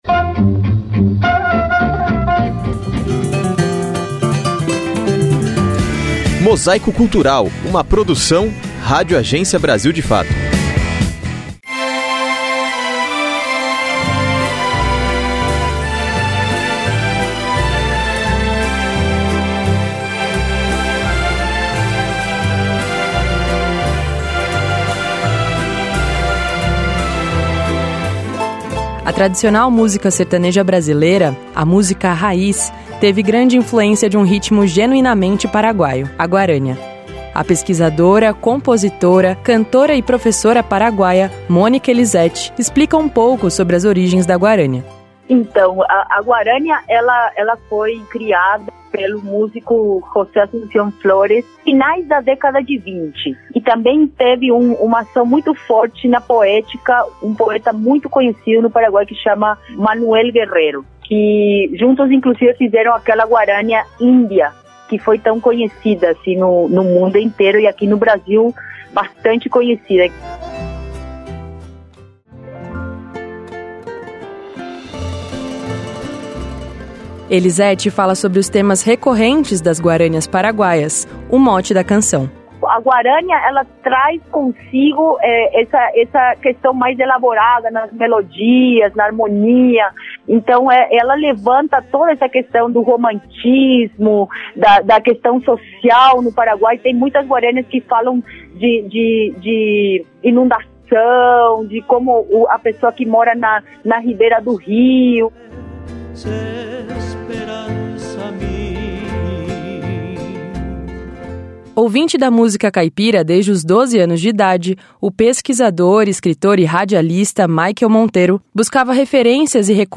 A tradicional música sertaneja brasileira, a chamada música raiz, da moda de viola, teve grande influência de um ritmo genuinamente paraguaio: a guarânia. É o que mostra esta reportagem musical